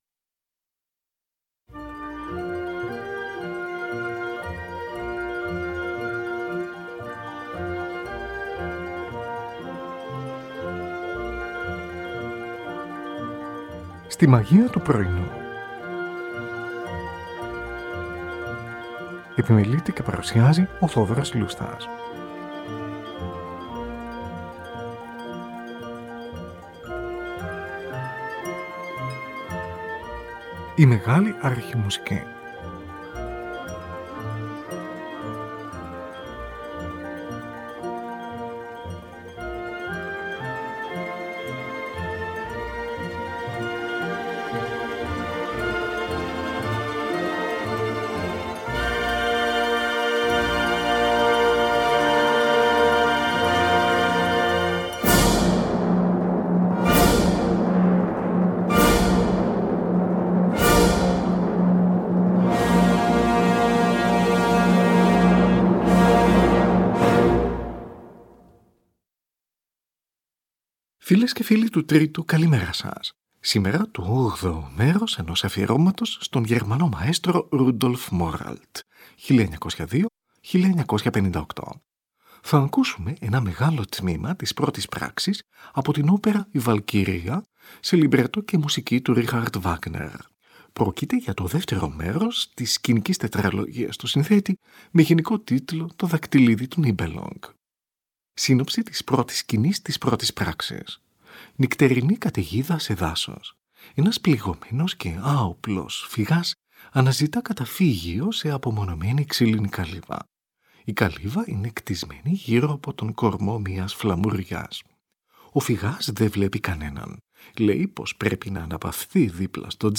Richard Wagner: τμήμα της πρώτης πράξης από την όπερα Η Βαλκυρία. Πρόκειται για το δεύτερο μέρος της σκηνικής τετραλογίας του συνθέτη με γενικό τίτλο Το δακτυλίδι του Nibelung.